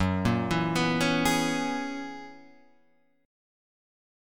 F# 11th